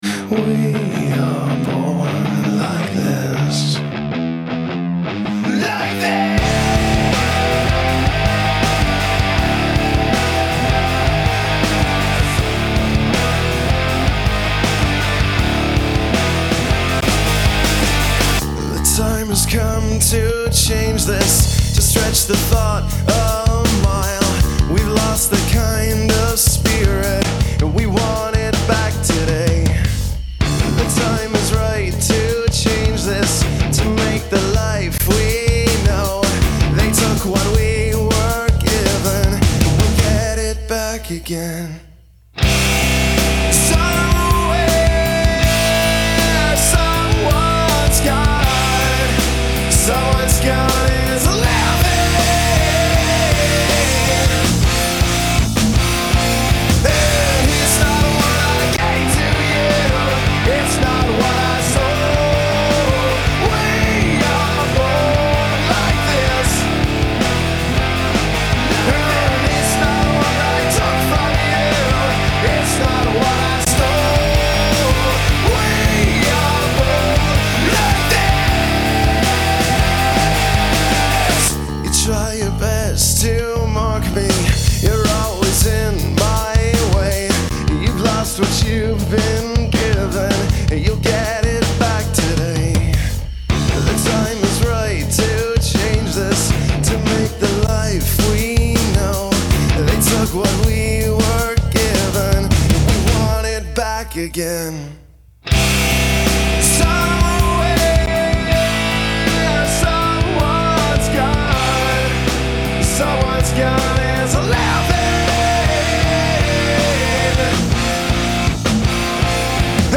CD track into realtek as AUX (my A.D.C. CD)input on my Sony STR V5 and randomly switched to TAPE 1 passing AUX through $25 preamps.
I'm not saying this is quality, but about how the soundstage is presented Attachments akaiPre_exp1.mp3 akaiPre_exp1.mp3 6.3 MB · Views: 72